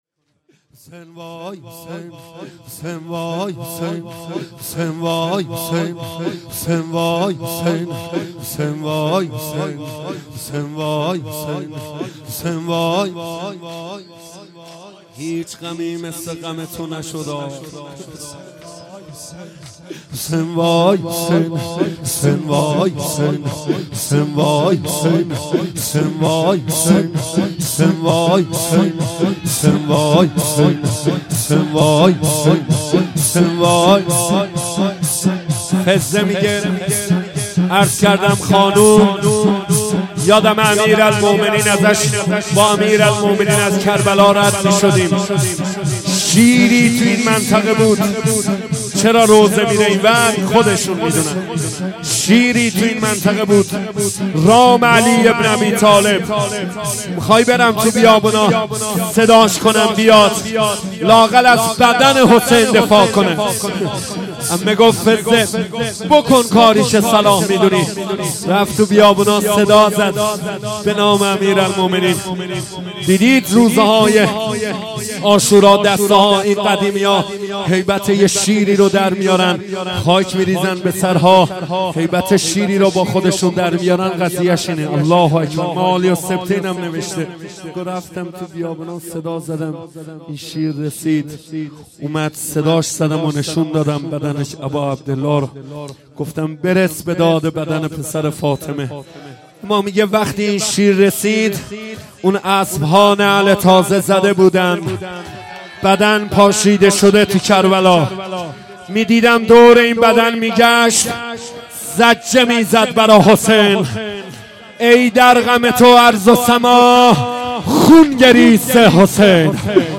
شور | نوای حسین